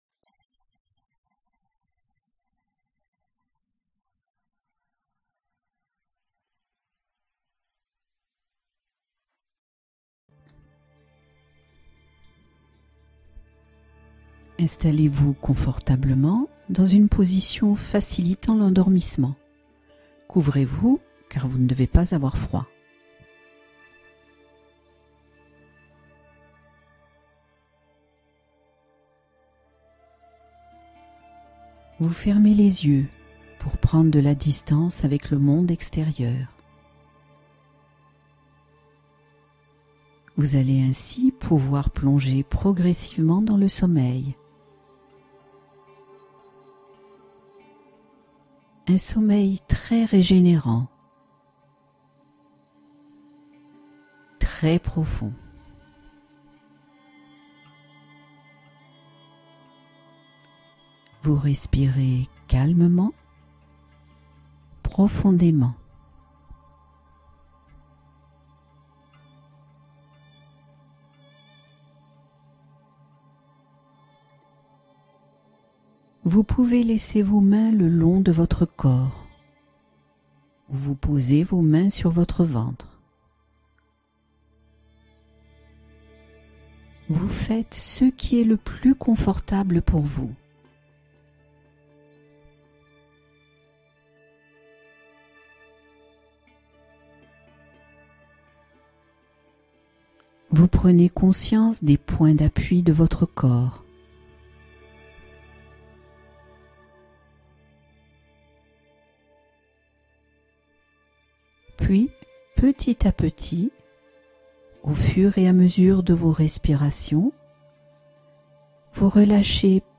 Relaxation guidée : sommeil réconfortant et profond